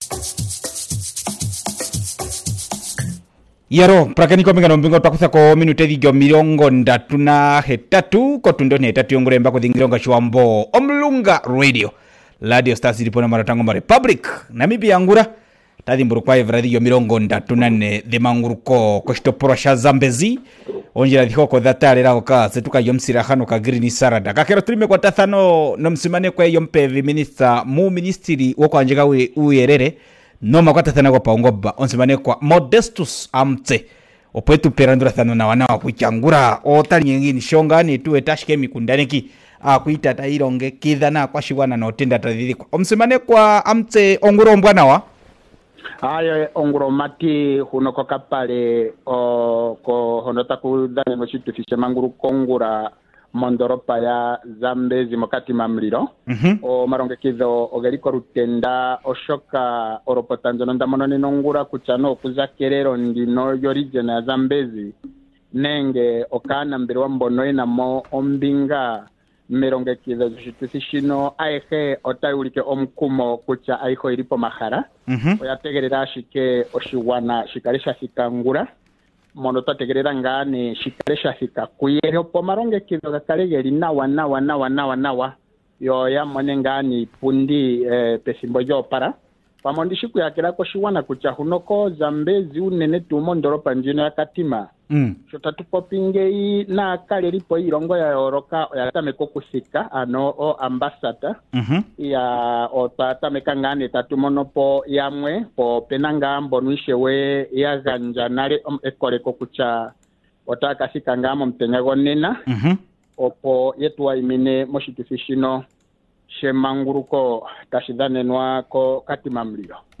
20 Mar Interview with deputy minister of MICT. Hon .Modestus Amutse